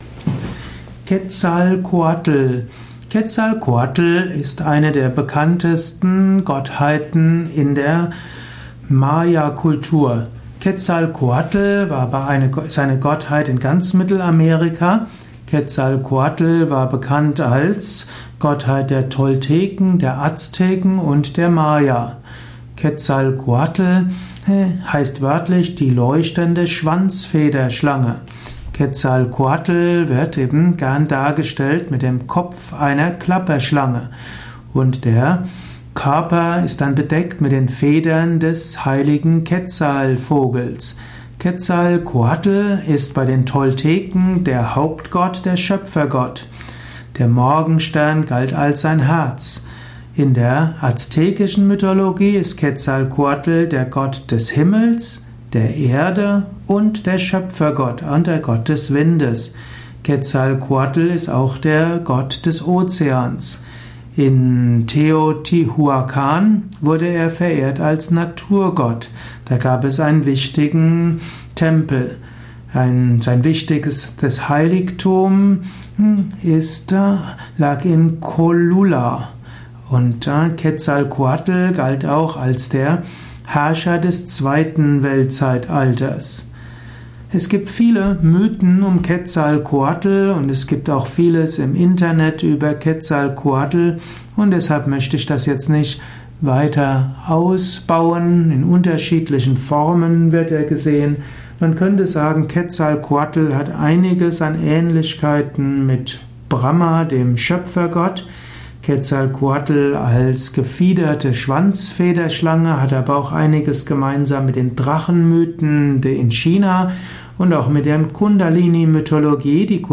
Eruierung der Bedeutung von Quetzalcoatl in der aztekischen Mythologie, im aztekischen Götterhimmel. Welche Bedeutung hat Gott Quetzalcoatl für die persönliche Entwicklung, den Lebensweg eines Aspiranten, einer Aspirantin? Dies ist die Tonspur eines Videos, zu finden im Yoga Wiki.